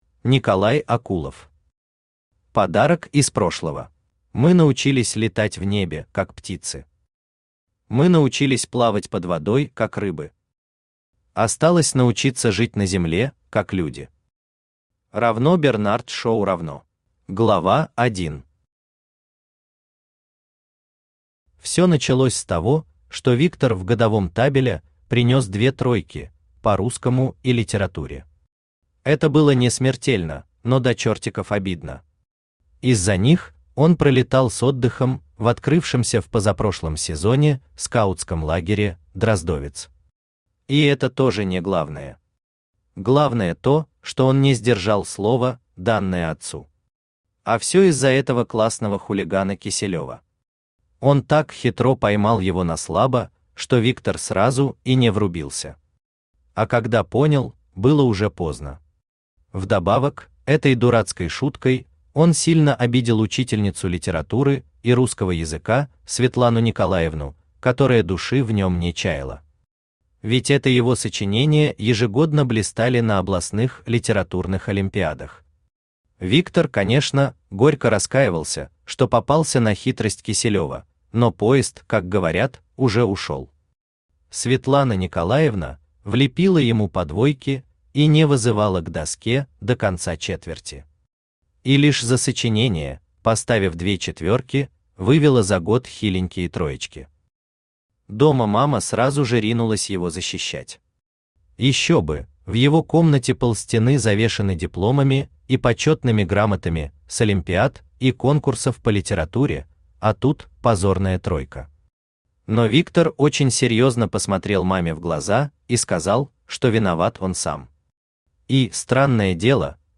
Аудиокнига Подарок из прошлого | Библиотека аудиокниг
Aудиокнига Подарок из прошлого Автор Николай Николаевич Акулов Читает аудиокнигу Авточтец ЛитРес.